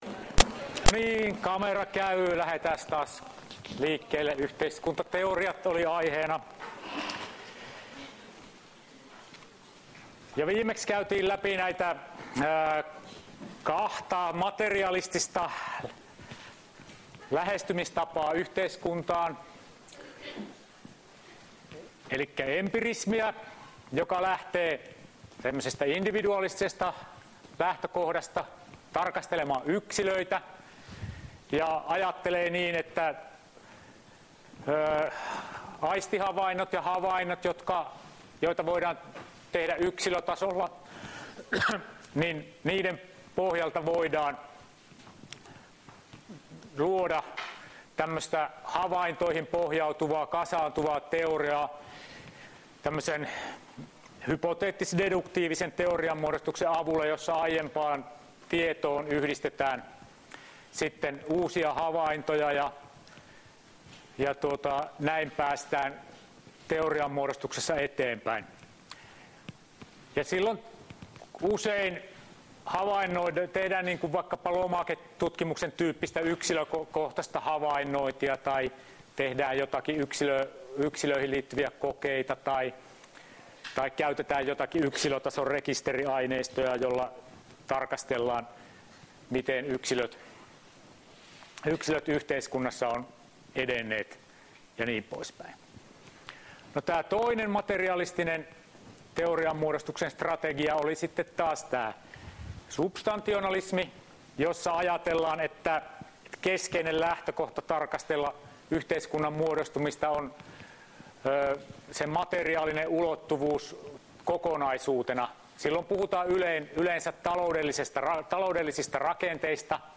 Luento 07.11.2018